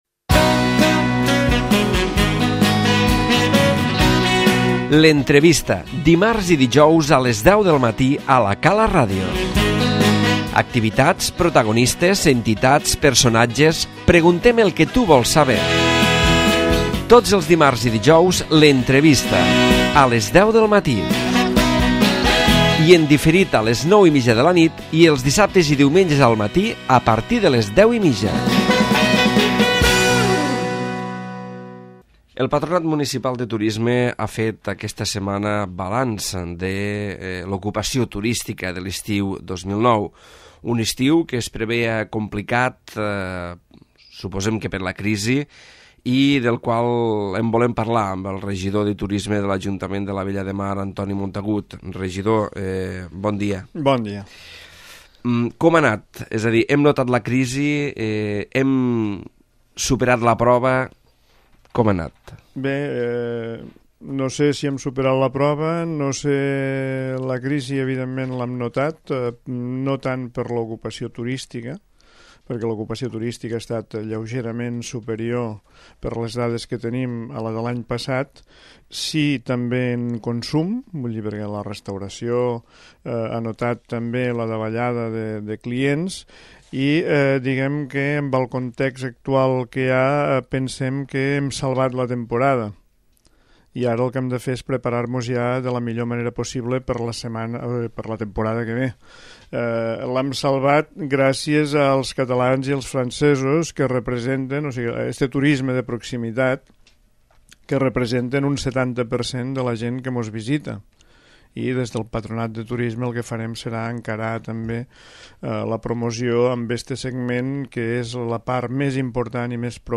L'Entrevista
A l'entrevista hem convidat Antoni Montagut, Regidor de Turisme de l'Ajuntament de l'Ametlla de Mar amb el que hem fet balanç de l'estiu 2009.